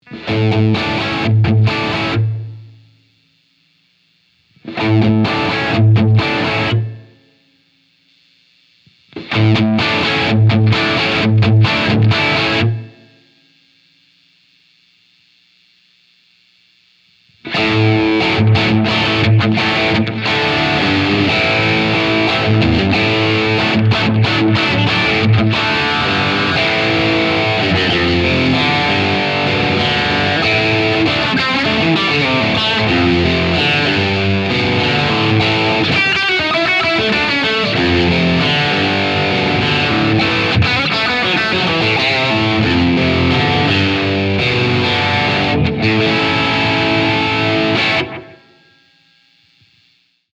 The bright tube preamp and a higher Mechanics value create a classic Rock ‘n’ Roll slapback.